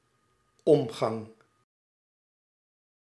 Ääntäminen
IPA: [kɔ.mɛʁs]